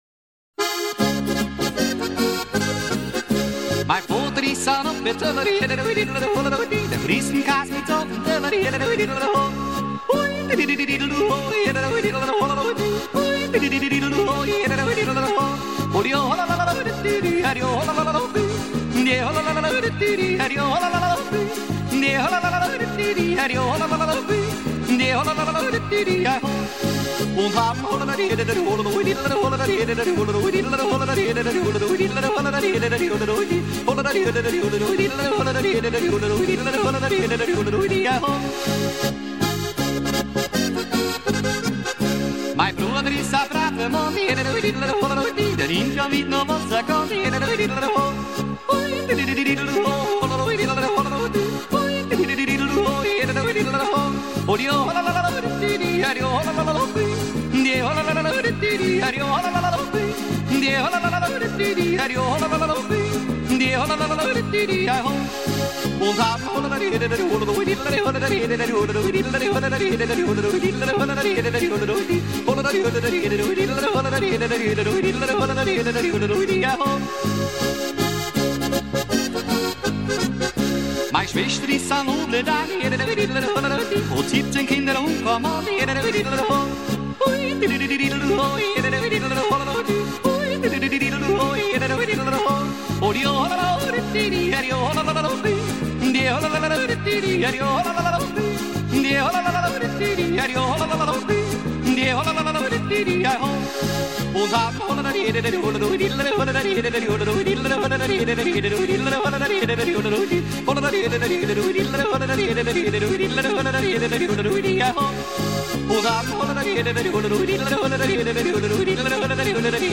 Альпийская пастушья песня